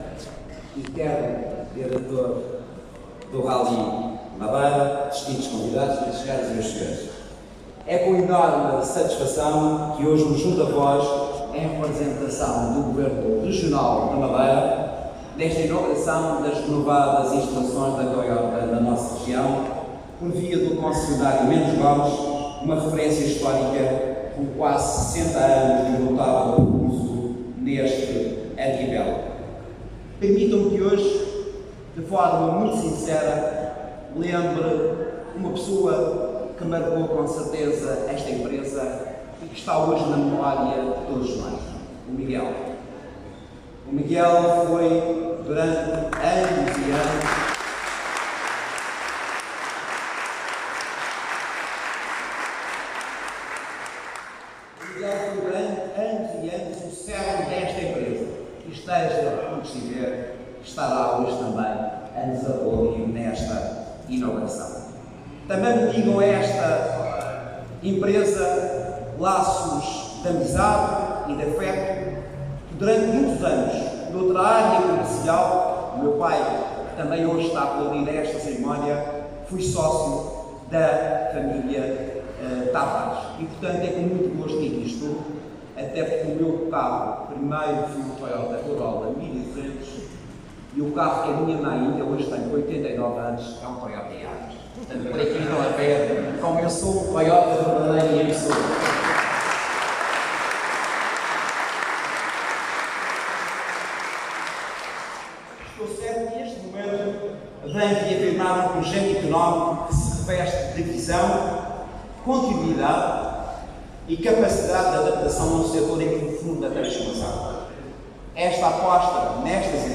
Secretário Regional da Economia enaltece investimento da Toyota na Madeira O Secretário Regional da Economia, José Manuel Rodrigues, presidiu hoje, em representação do Presidente do Governo Regional, à cerimónia de inauguração das novas instalações da Toyota na Madeira (Poço Barral – Funchal), marca presente na Região desde 1968, através do concessionário Mendes Gomes.